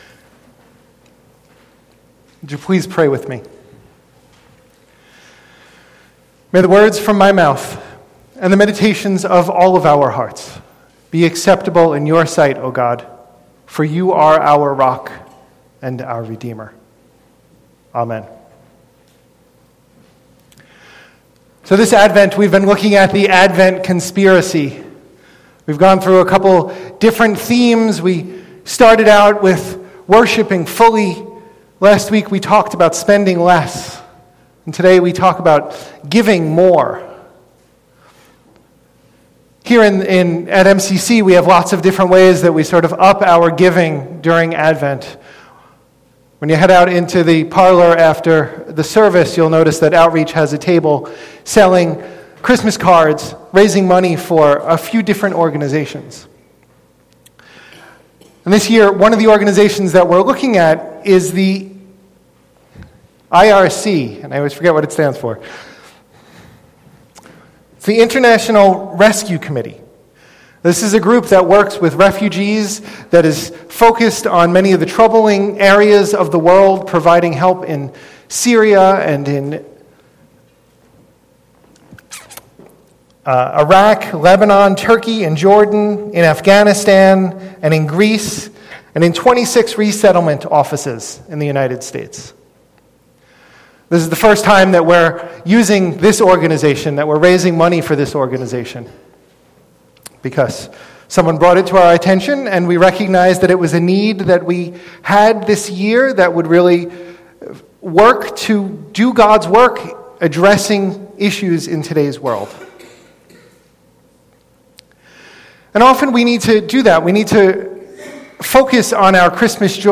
The letter read during the sermon, “Dear non-Muslim allies,” can be found here.